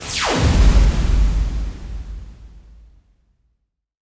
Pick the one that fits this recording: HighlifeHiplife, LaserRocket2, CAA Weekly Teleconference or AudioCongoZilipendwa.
LaserRocket2